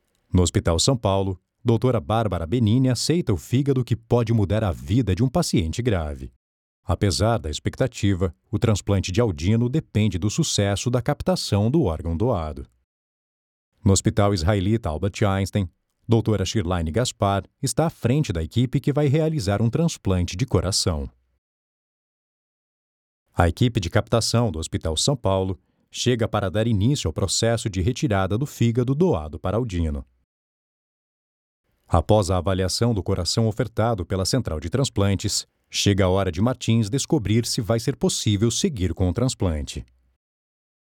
Documentaries
BassDeepLowVery Low
FriendlyWarmConversationalDarkCharming